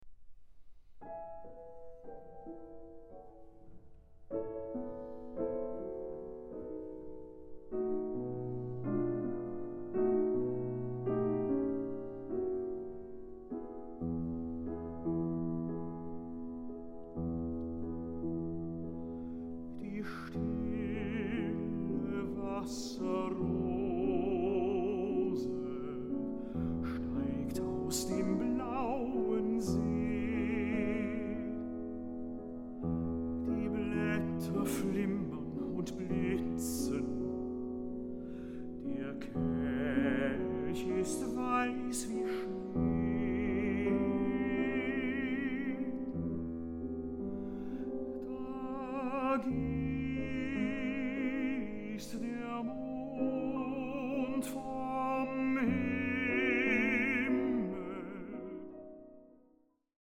soprano
tenor
piano